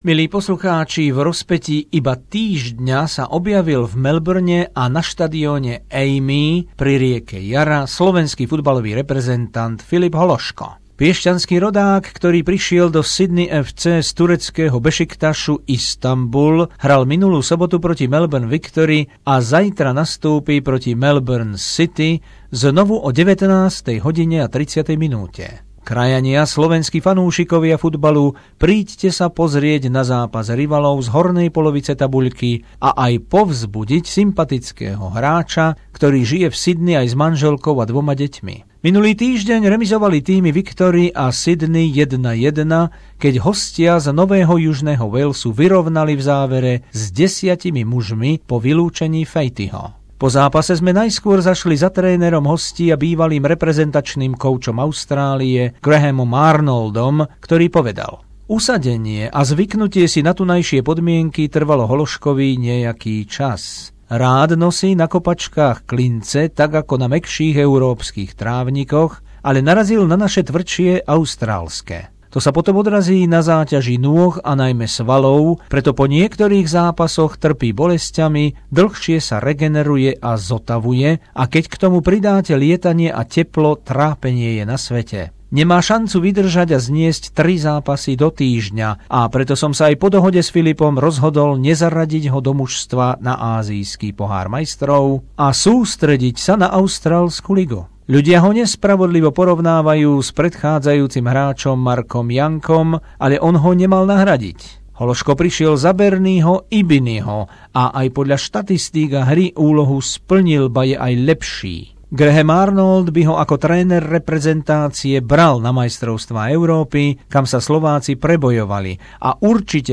Rozhovor s trénerom Sydney FC Grahamom Arnoldom a futbalistom Filipom Hološkom po zápase v Melbourne s Victory 1:1 v sobotu 27. februára 2016